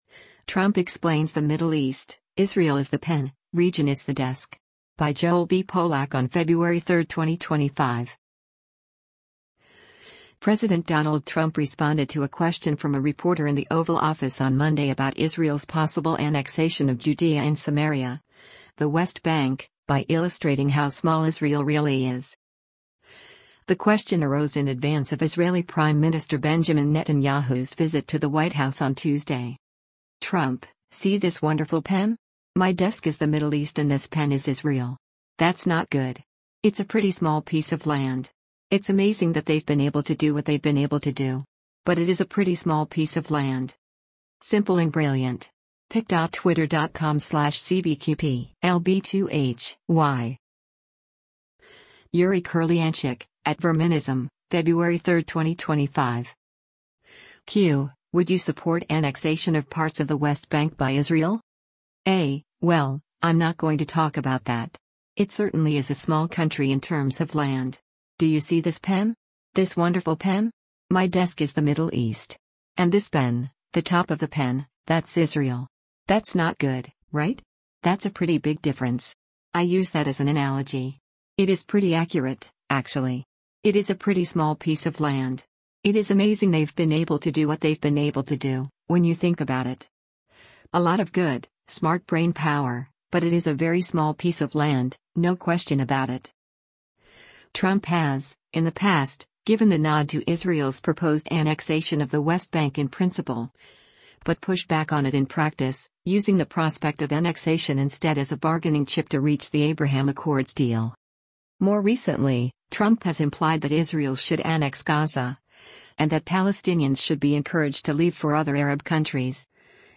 President Donald Trump responded to a question from a reporter in the Oval Office on Monday about Israel’s possible annexation of Judea and Samaria (the West Bank) by illustrating how small Israel really is.